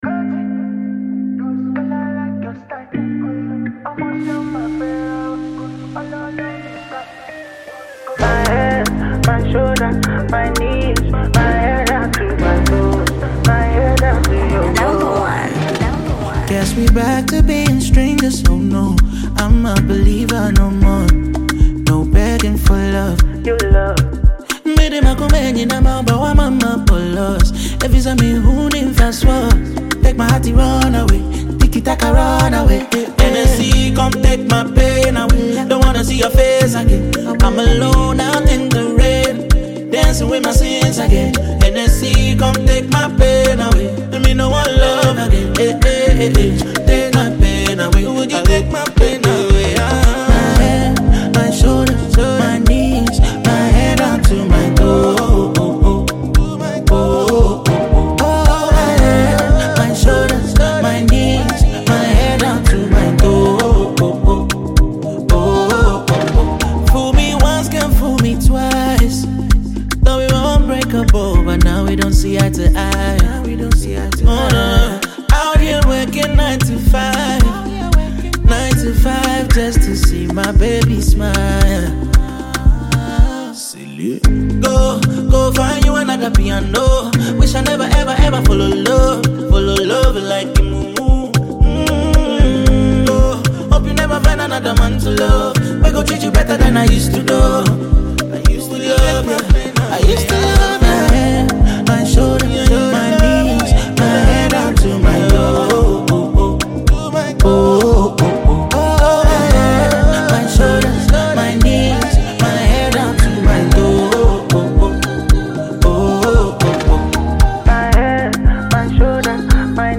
upbeat track
smooth vocals and catchy melodies